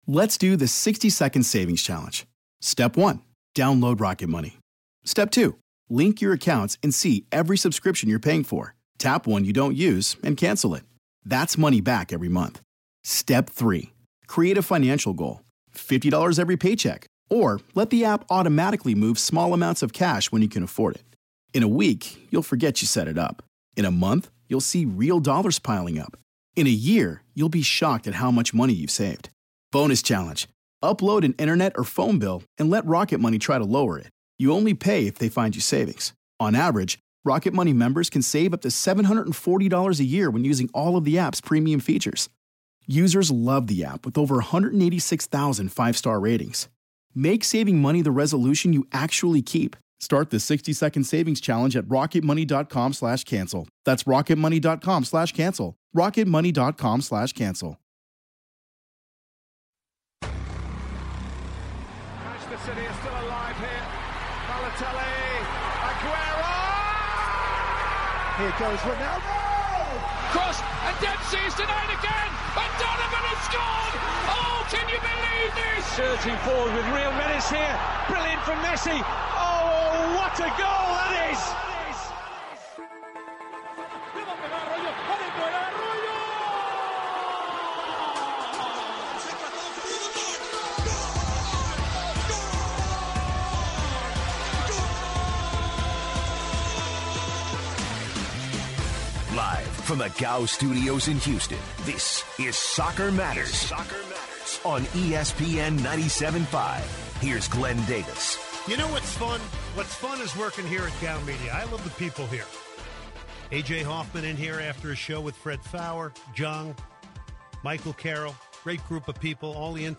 He also takes calls and discusses the Women's World Cup Final and how the USA team has been playing. He talks about the Men's National Team in the Gold Cup. He also has listeners chime in to discuss Landon Donovan's recent controversial tweet.